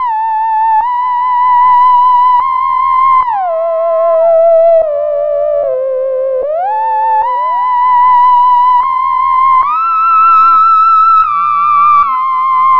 10_Theremint_170_C.wav